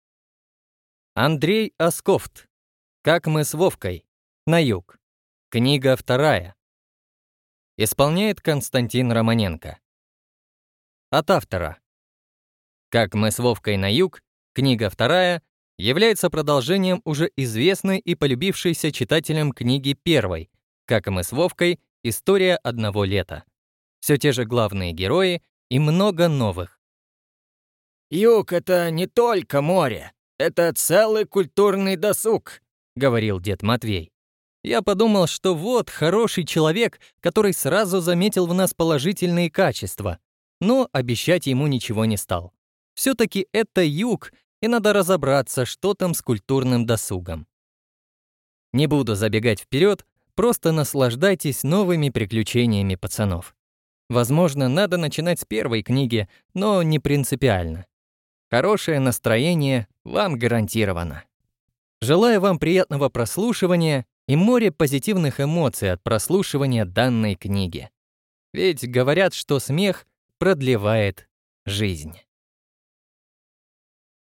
Аудиокнига Как мы с Вовкой. На Юг. Книга вторая | Библиотека аудиокниг